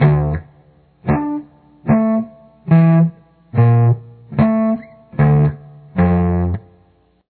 Now practice playing the riff one note at a time: